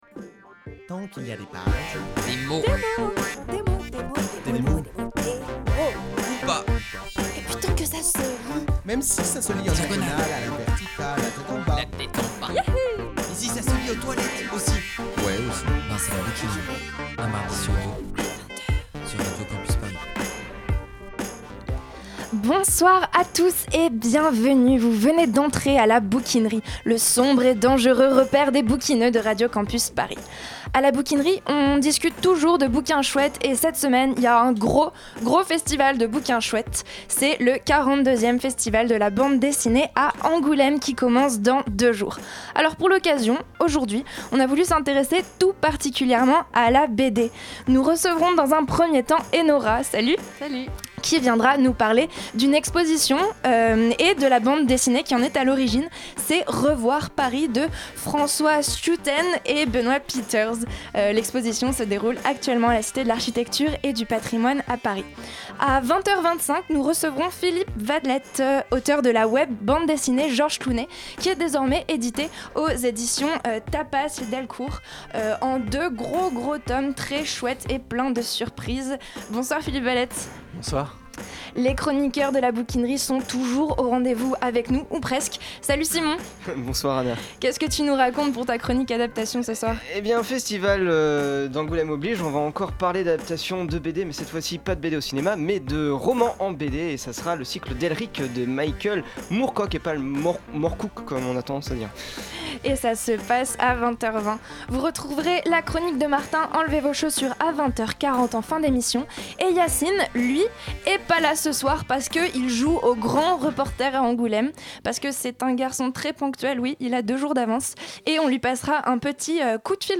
La Bouquinerie : Trop de dessinance avec G. Clooney Partager Type Entretien Culture mercredi 28 janvier 2015 Lire Pause Télécharger La Bouquinerie, ça parle toujours de bouquins chouettes.